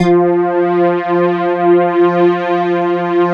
SYN JX49 09R.wav